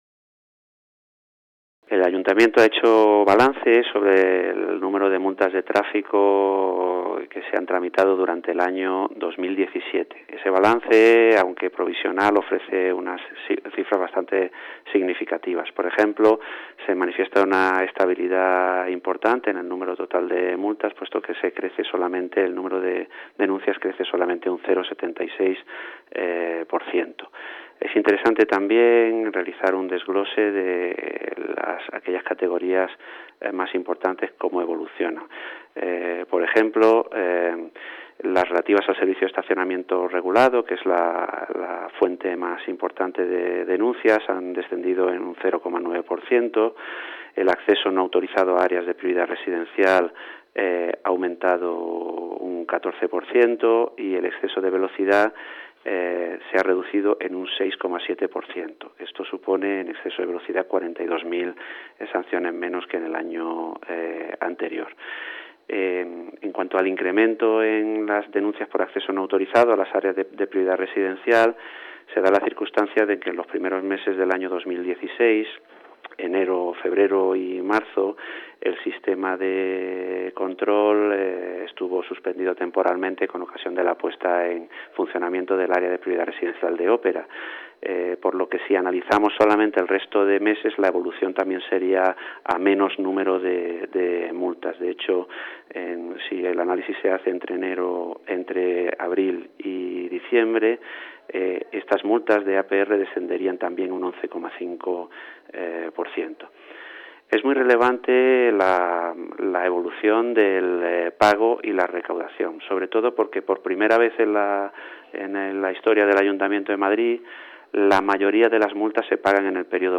Nueva ventana:Francisco López Carmona, director general de Gestión y Vigilancia de la Circulación, hace balance de las multas en 2017